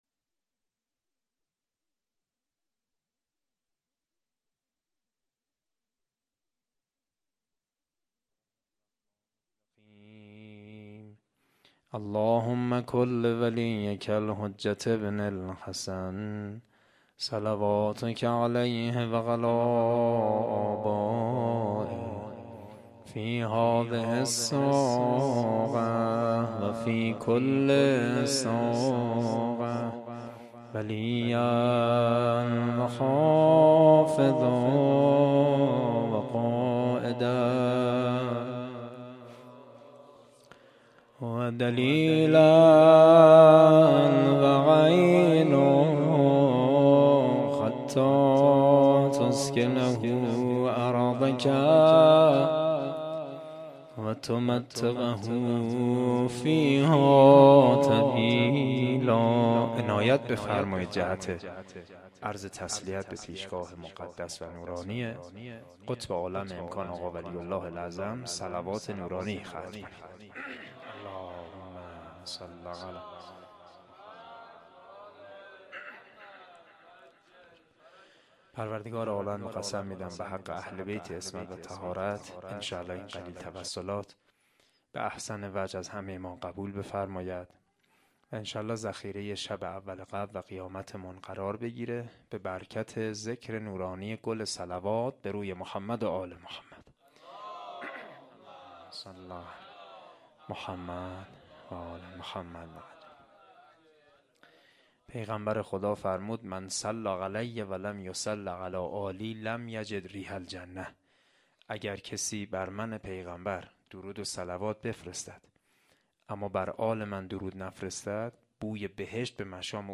asheghan-sokhanrani1.mp3